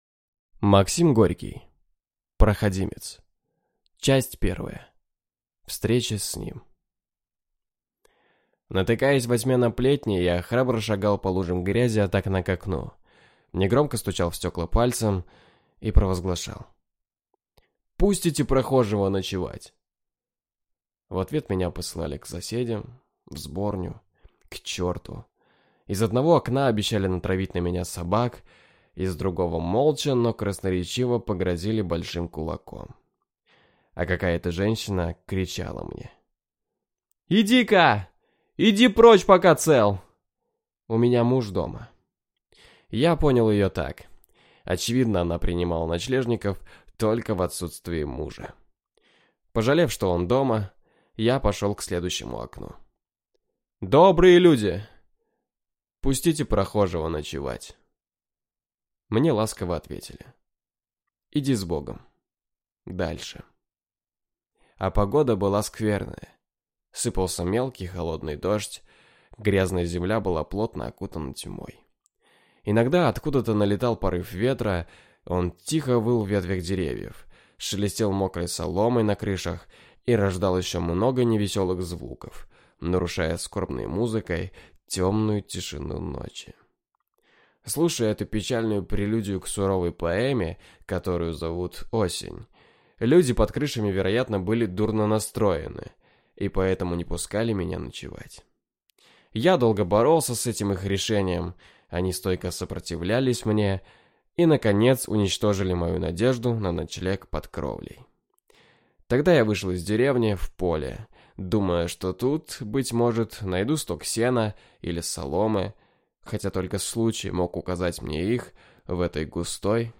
Aудиокнига Проходимец